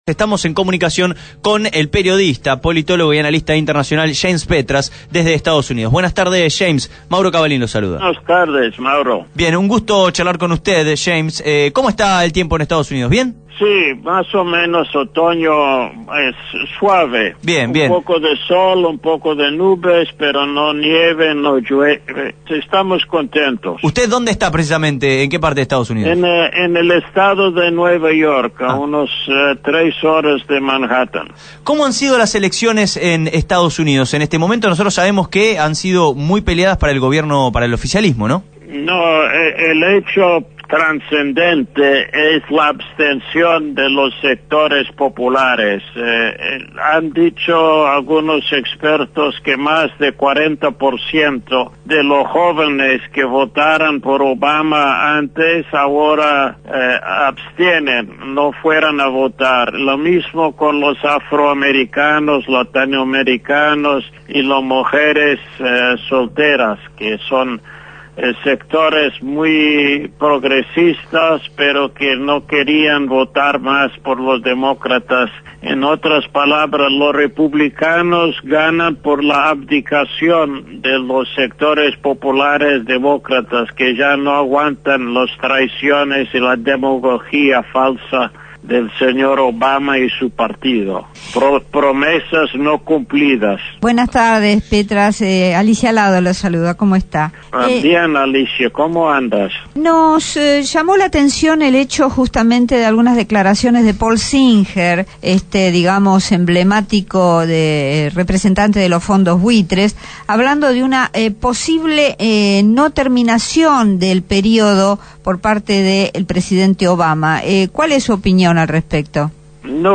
James Petras, sociólogo y escritor estadounidense, fue entrevistado en el programa Abramos la Boca a raíz de las elecciones en Estados Unidos y la situación financiera internacional que tiene en el centro de la escena a la República Argentina.